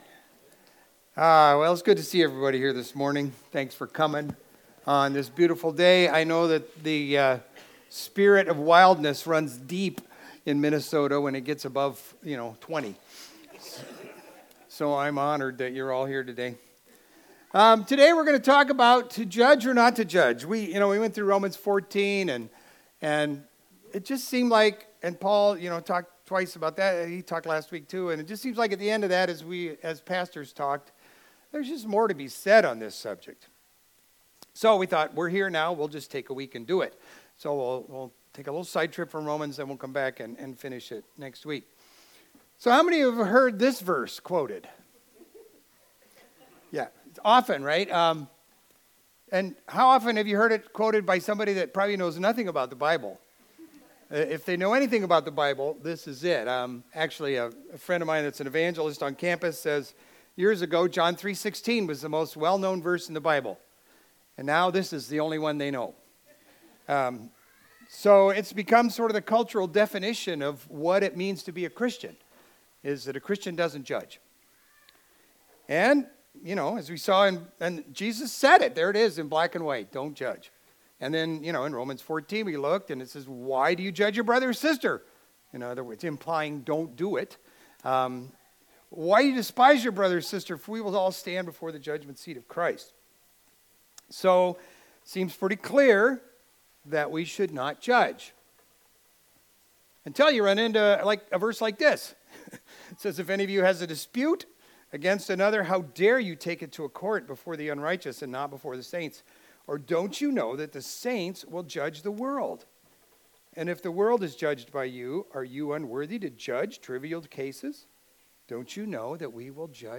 Video Audio Download Audio Home Resources Sermons To Judge or Not to Judge Feb 15 To Judge or Not to Judge Everyone knows that Christians are not to judge.